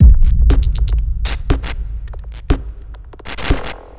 TSNRG2 Breakbeat 013.wav